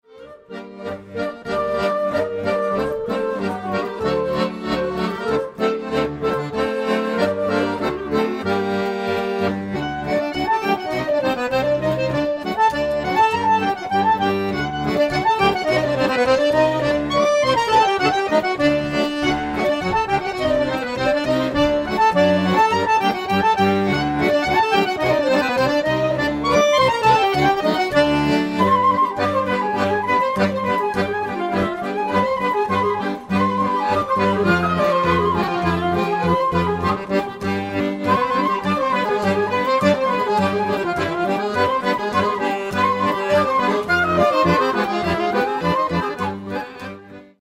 Akkordeon
Querflöte, Altquerflöte, Kontrabass
Klarinette, Bassklarinette, Tenorsaxophon